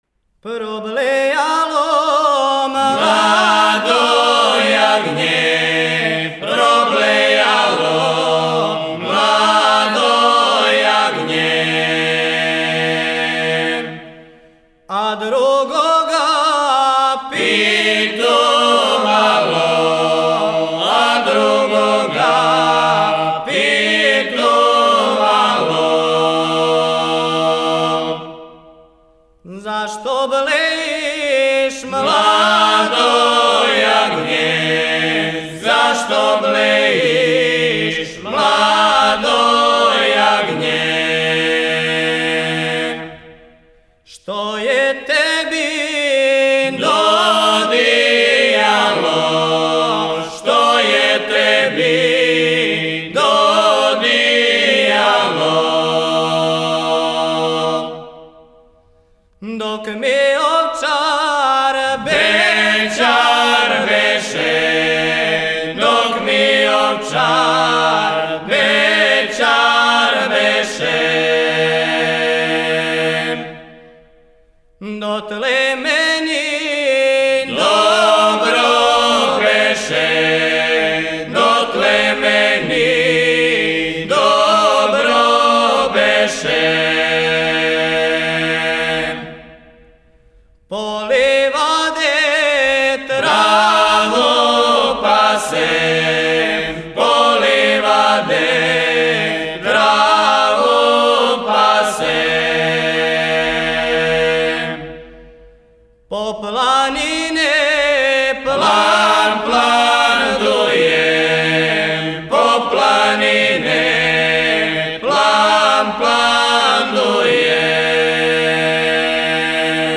Начин певања: На бас
Напомена: Чобанска песма